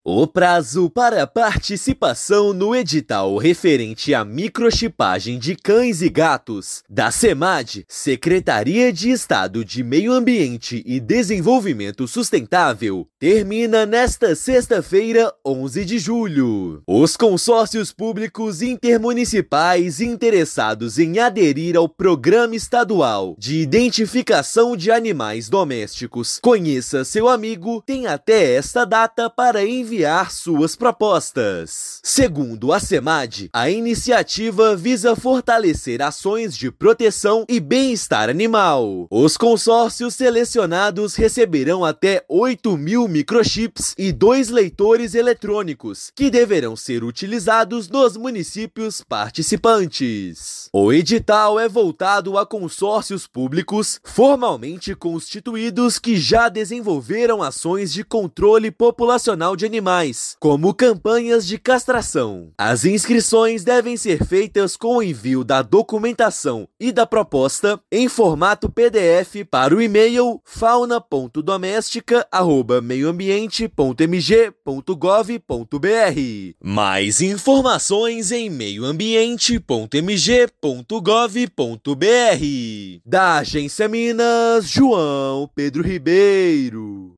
Agência Minas Gerais | [RÁDIO] Prazo para edital de microchipagem de cães e gatos em Minas Gerais termina nesta sexta-feira (11/7)